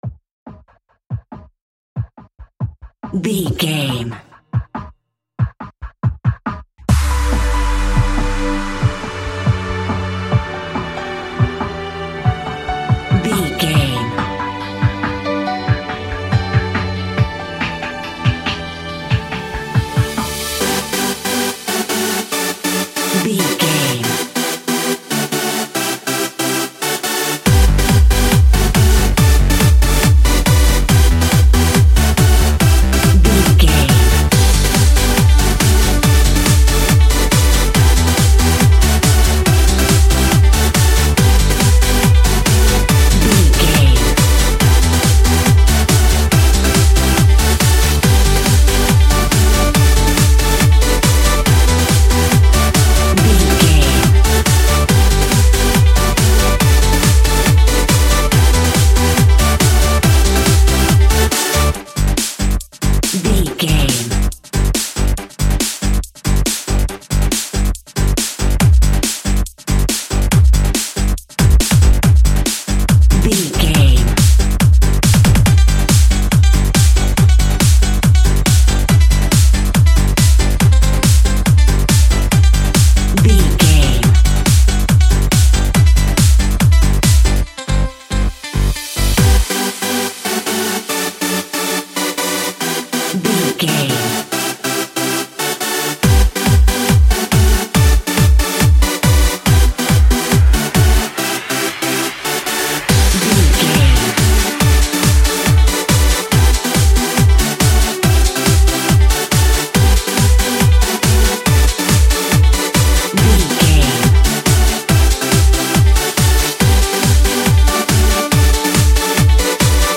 Ionian/Major
Fast
groovy
uplifting
driving
energetic
repetitive
drum machine
piano
strings
acoustic guitar
synthesiser
house
electro dance
techno
trance
instrumentals
synth leads
synth bass
upbeat